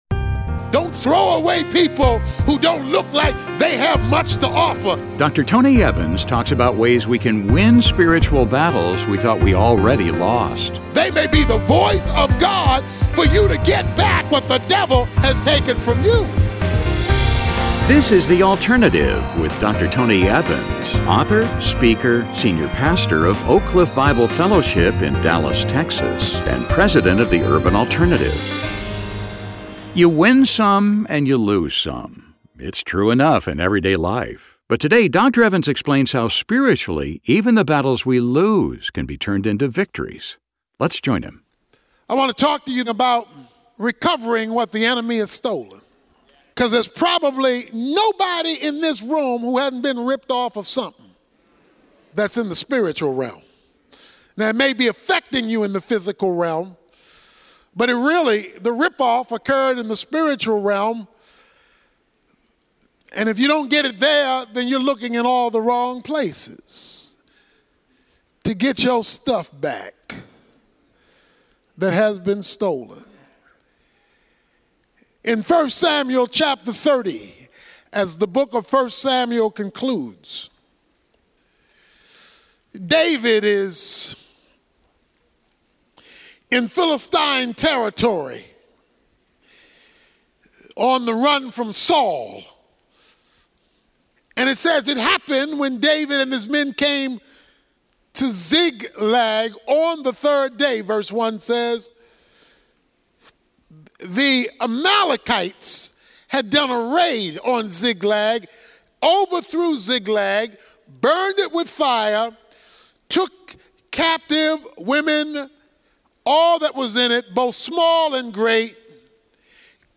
Tony Evans Sermons Recovering What the Enemy has Stolen, Part 2 Play Episode Pause Episode Mute/Unmute Episode Rewind 10 Seconds 1x Fast Forward 30 seconds 00:00 / Subscribe Share RSS Feed Share Link Embed